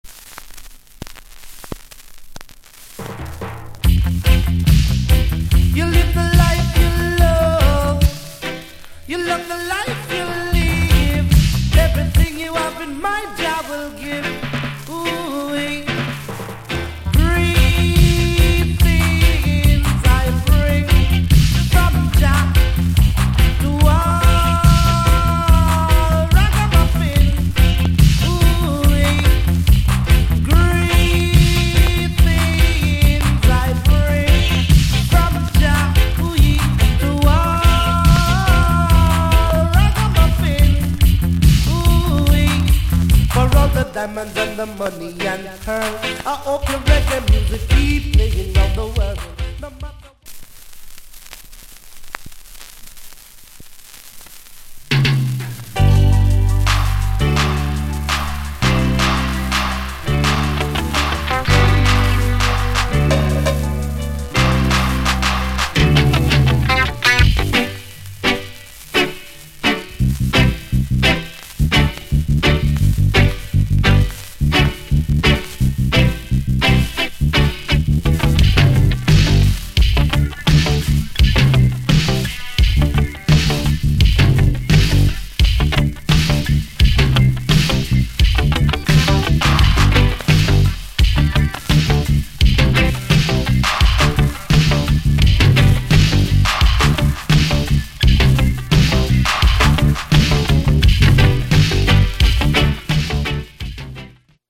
** 途中傷による周期的なパチノイズあり。